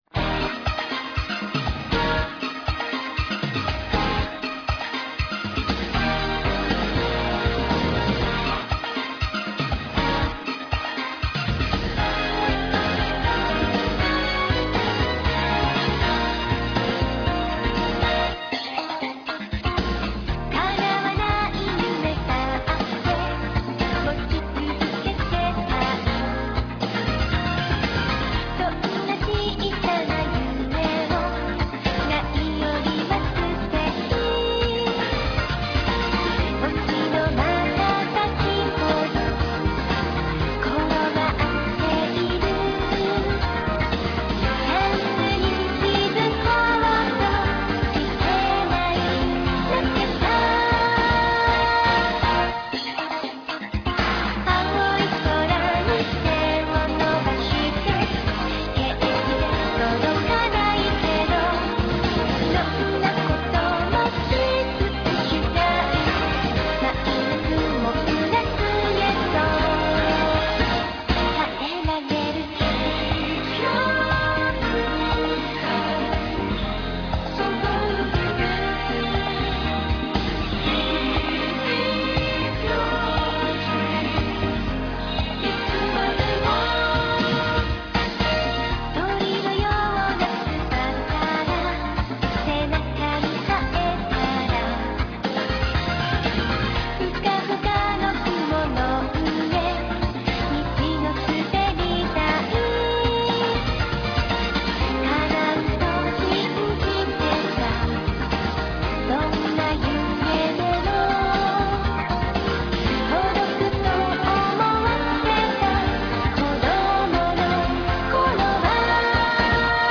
It has a happy tone to it.